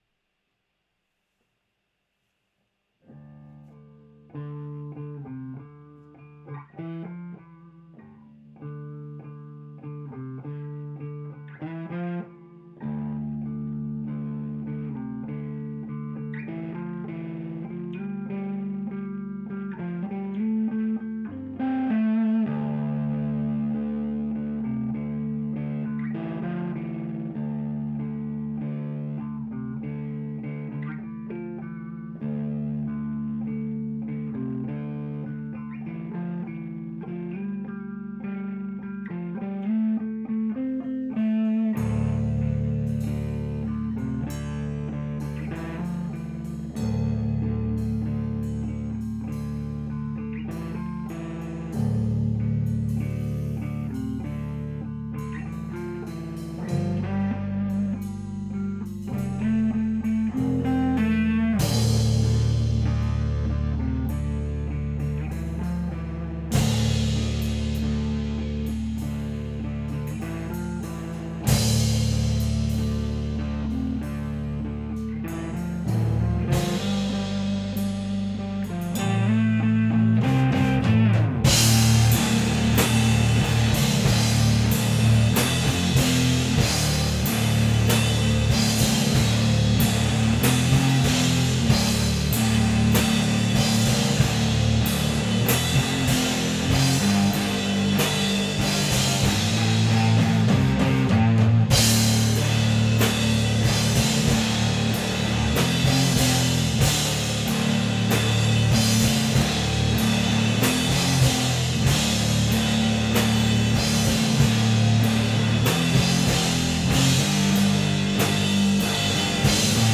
Stoner/Doom/Sludge - die sumpfigen Untiefen
Ich bin nicht mehr, ich bin zugleich Ein Tropfen Licht Im Glanz in Ewigkeit (Hintergrundinfo: 2-Kanalige Probraumaufnahme) Anhang anzeigen 966066